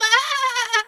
sheep_2_baa_high_04.wav